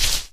default_dig_crumbly.ogg